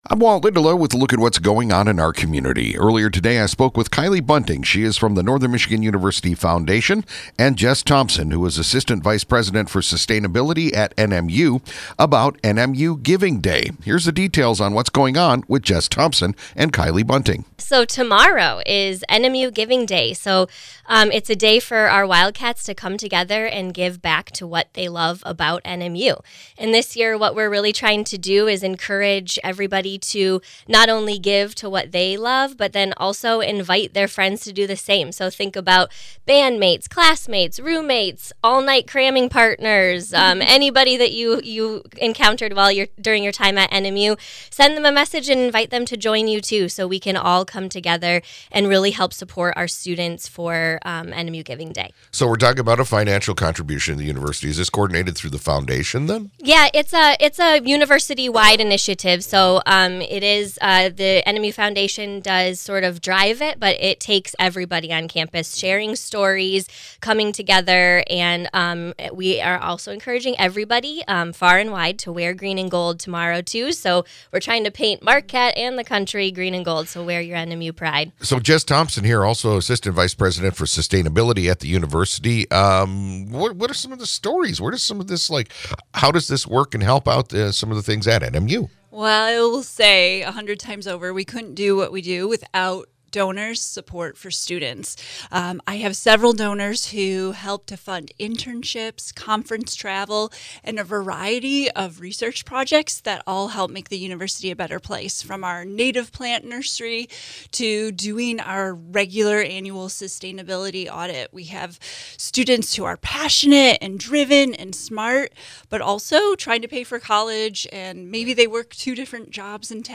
Home Featured Posts The Sunny Morning Show NMU Giving Day Is Here For 2026!